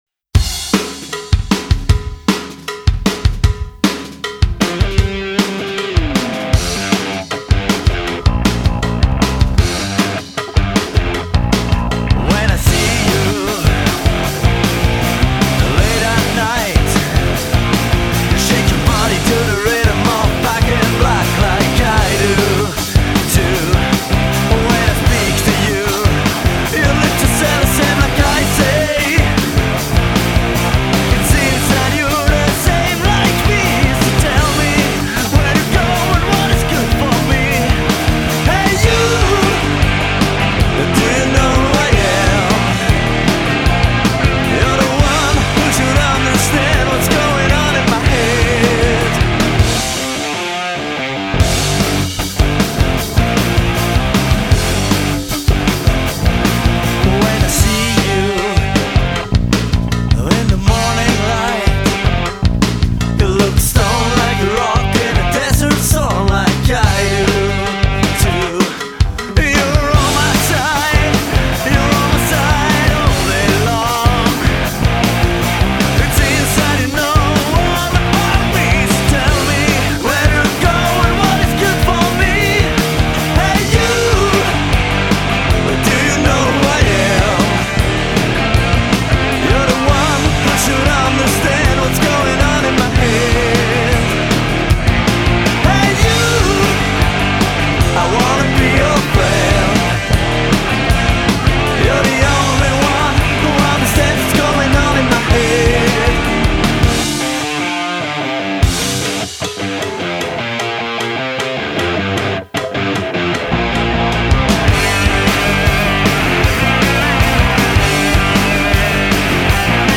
Heavy metal
Punk
Rock & Roll